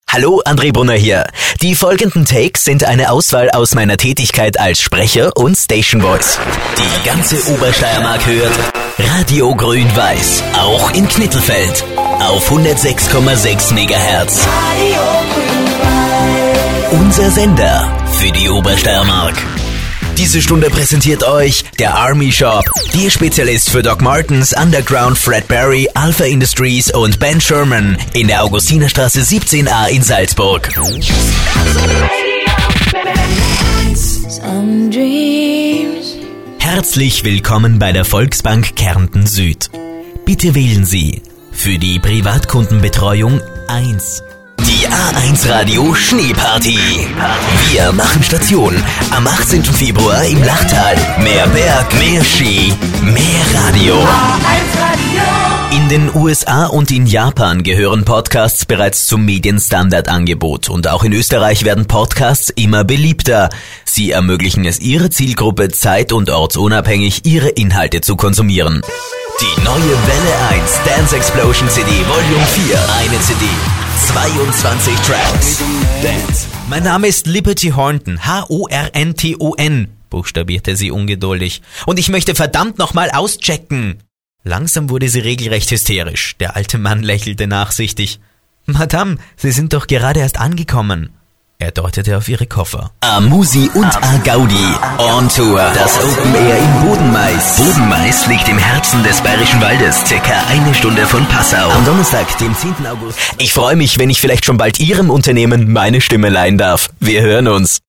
deutschsprachiger Sprecher.
Sprechprobe: Werbung (Muttersprache):
german voice over artist